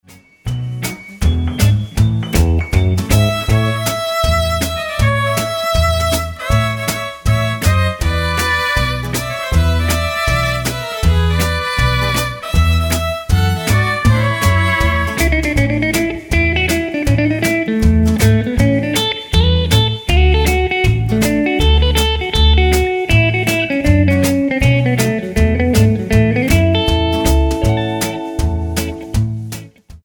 Tonart:A ohne Chor